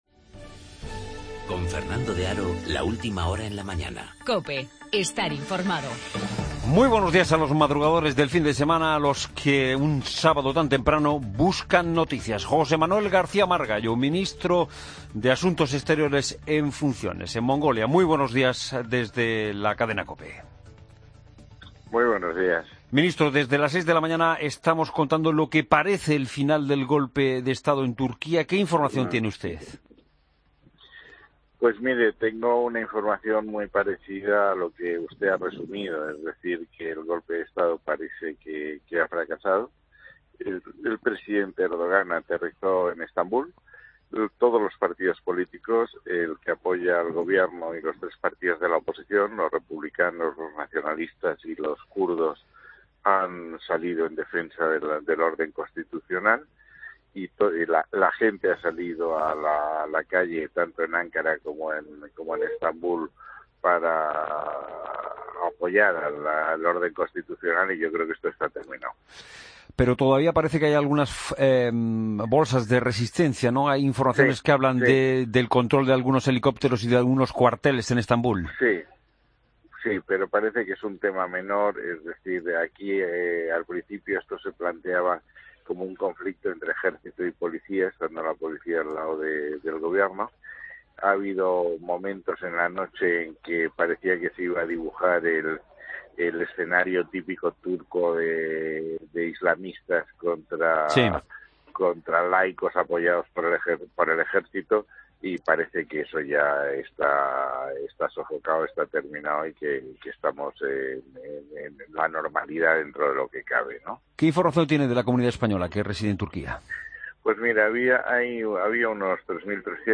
AUDIO: Entrevista al ministro de Asuntos Exteriores en funciones tras el golpe de Estado en Turquía